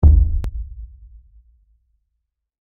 دانلود آهنگ تصادف 5 از افکت صوتی حمل و نقل
جلوه های صوتی